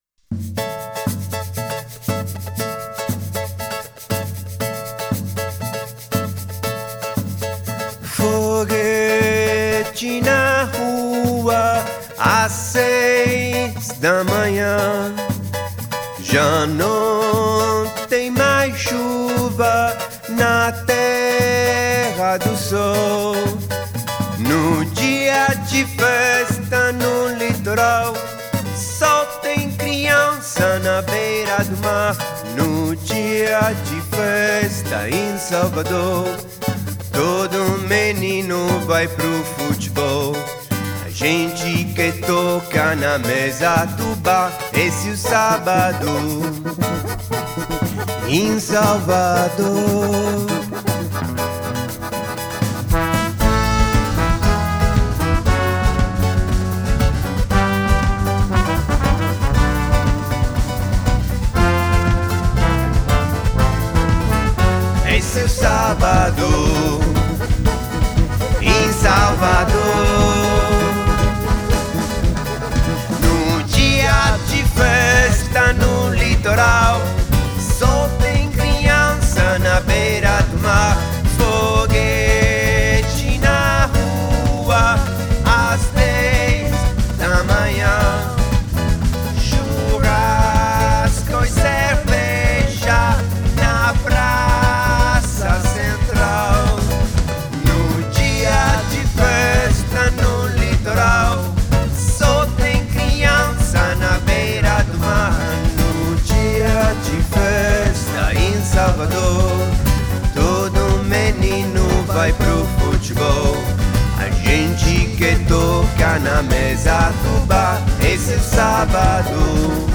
Il ritmo unico dello spaghetti samba da Bologna!